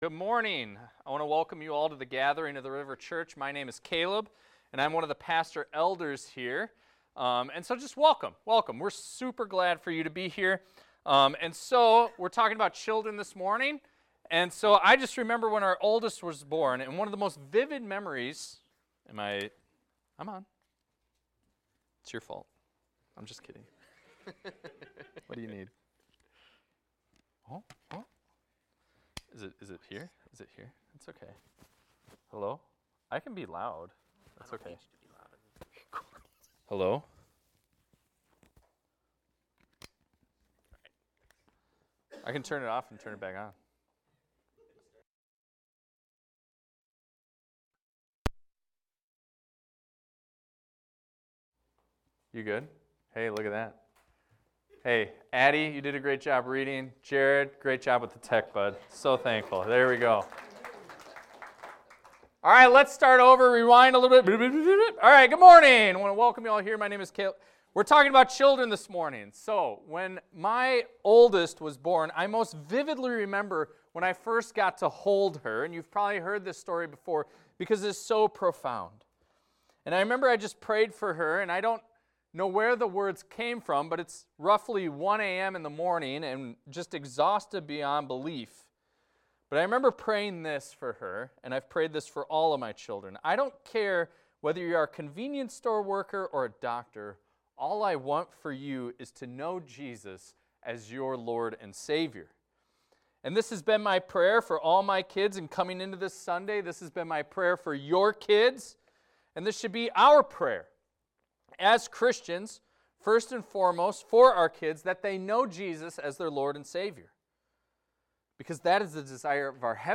This is a recording of a sermon titled, "Parents and Children."